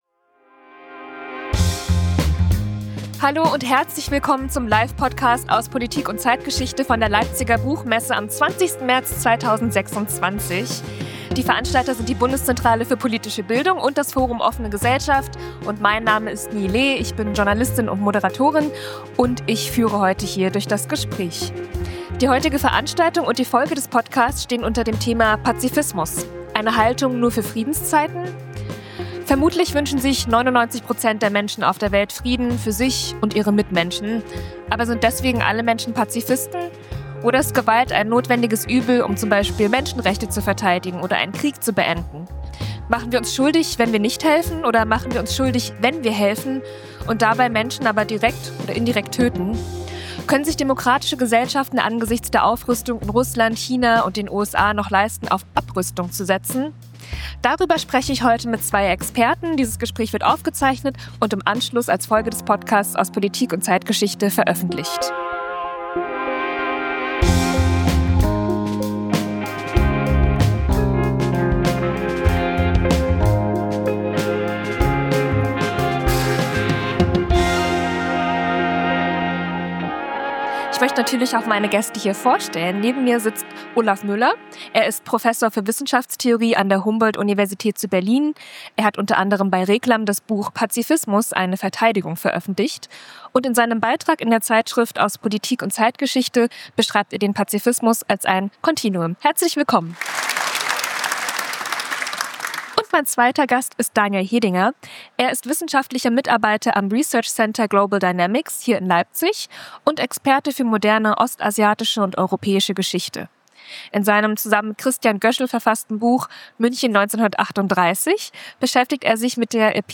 Das Gespräch haben wir am 20. März 2026 auf der Leipziger Buchmesse aufgezeichnet.